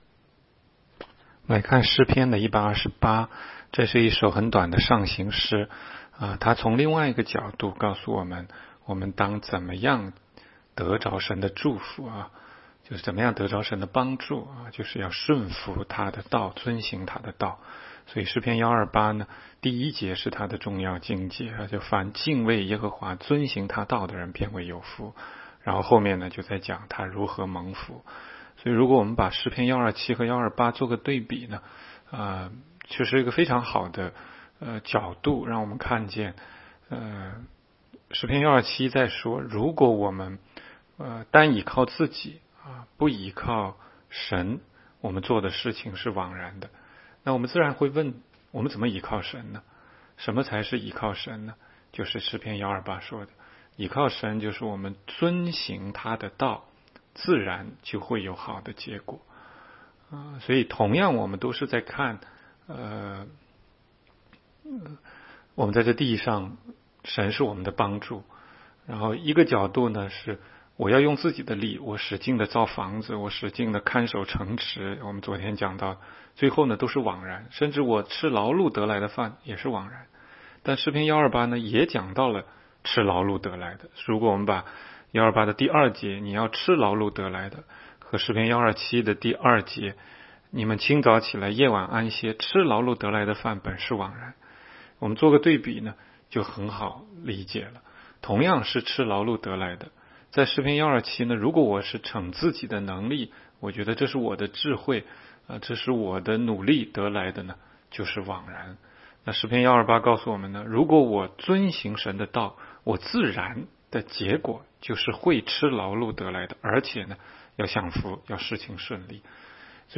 16街讲道录音 - 每日读经 -《 诗篇》128章